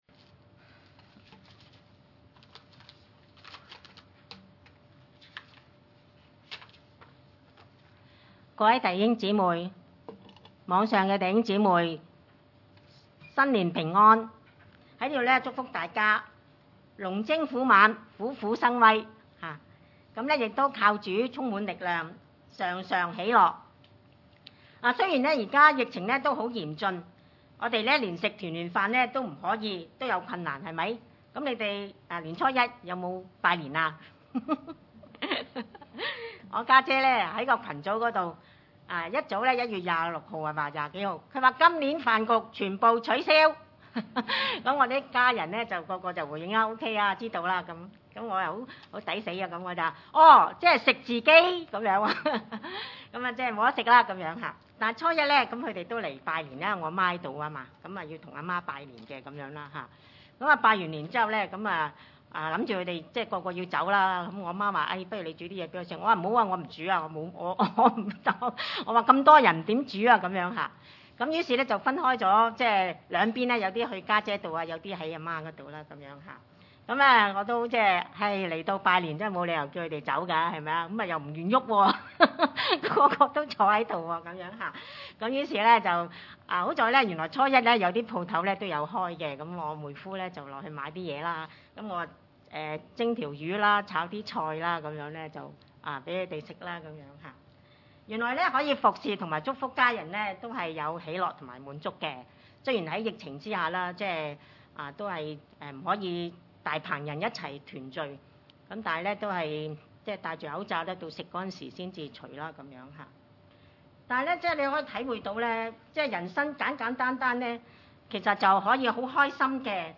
10-14 崇拜類別: 主日午堂崇拜 腓三:1 1.